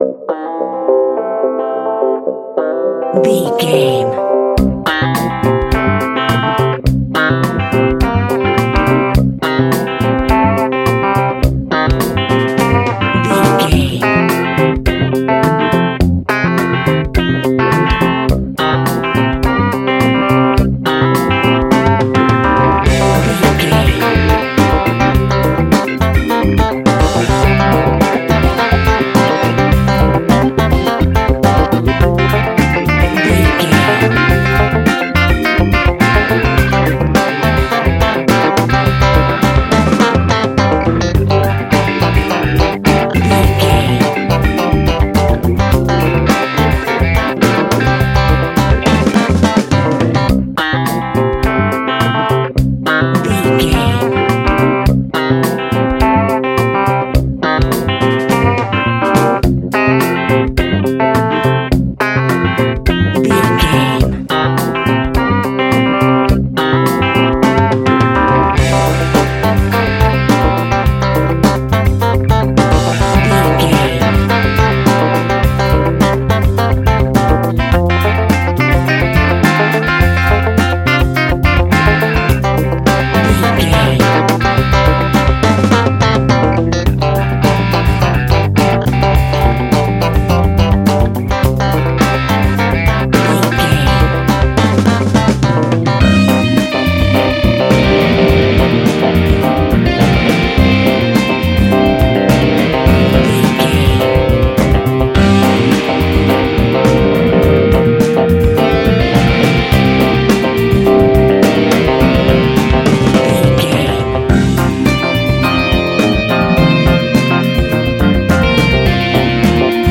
Ionian/Major
groovy
funky
lively
electric guitar
electric organ
drums
bass guitar
saxophone
percussion